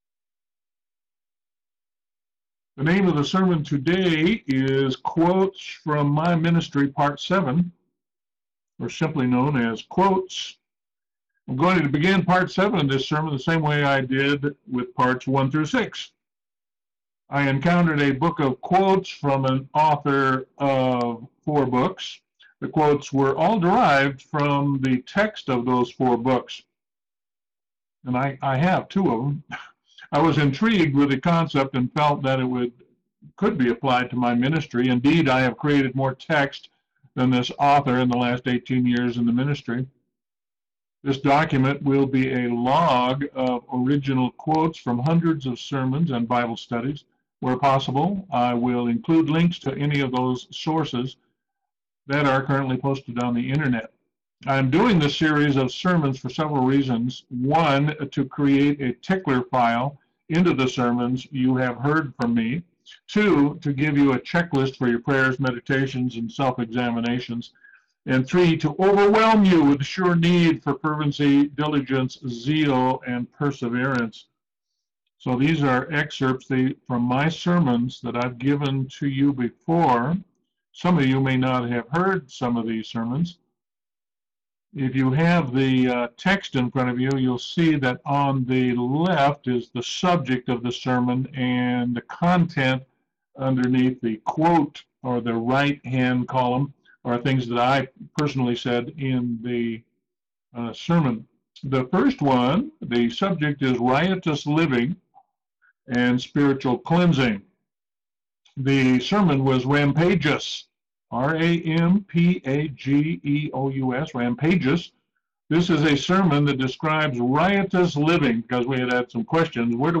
Sermon: Quotes from My Ministry - Part 7